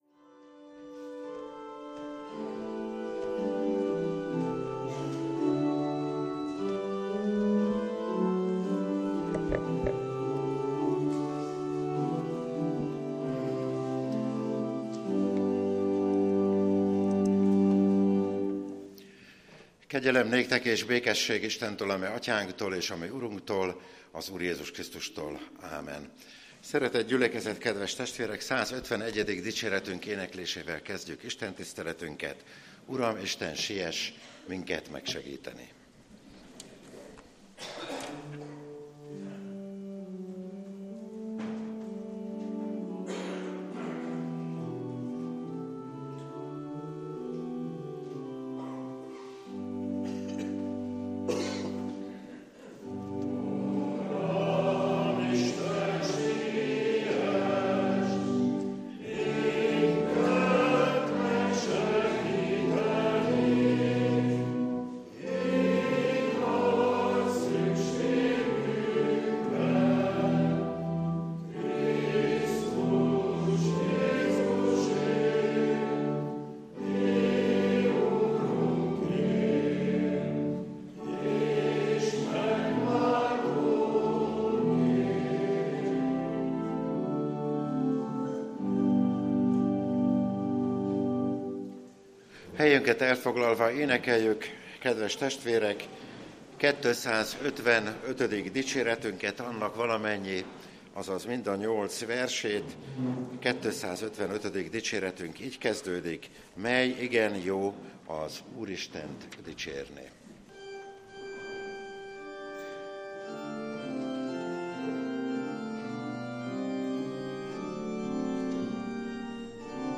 – Budahegyvidéki Református Egyházközség